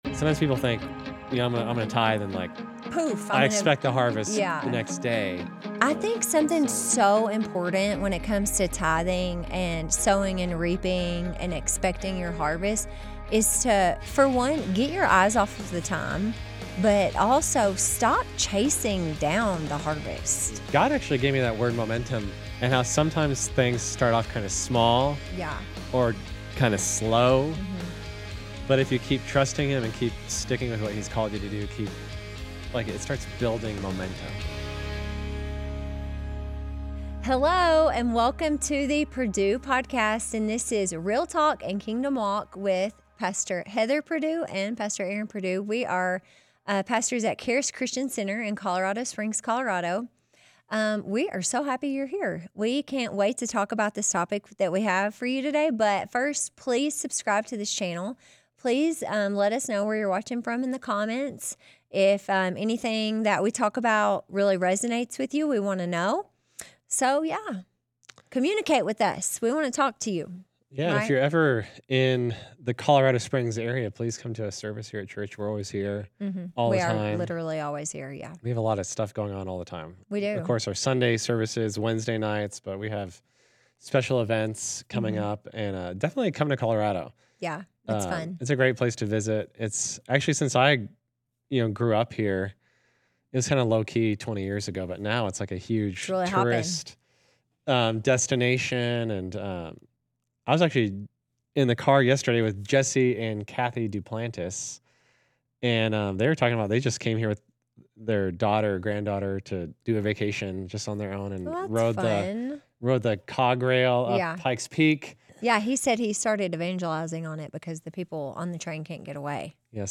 Whether you’re feeling stuck or ready to accelerate, this conversation will encourage you to embrace small beginnings and keep sowing.